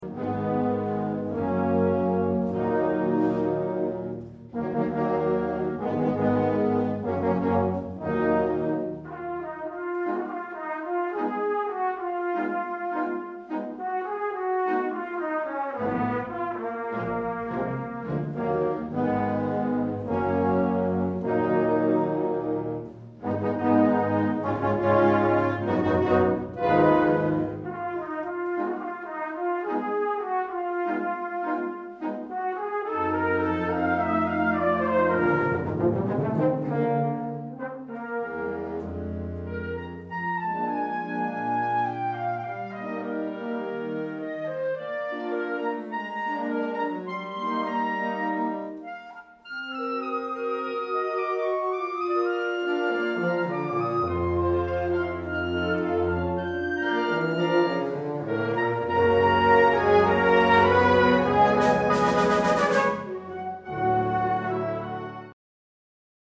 symphonic and military marches and scherzos